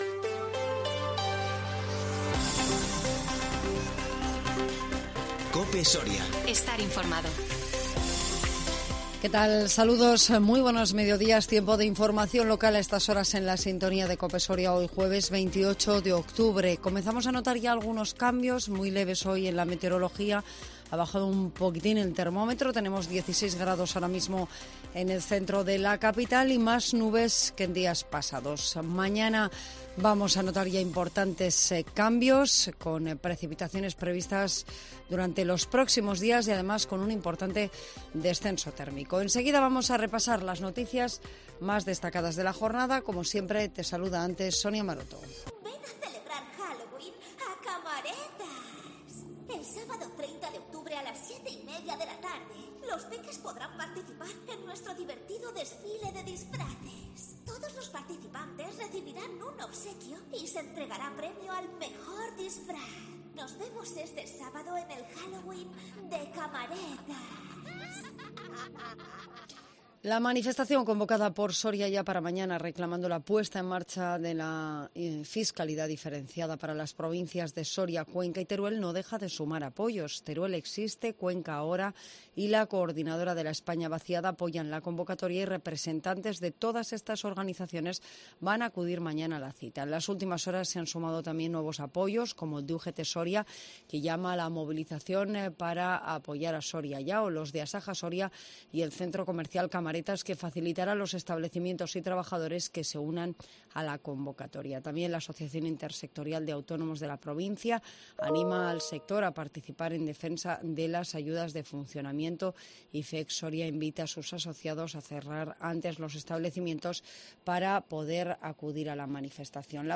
INFORMATIVO MEDIODÍA 28 OCTUBRE 2021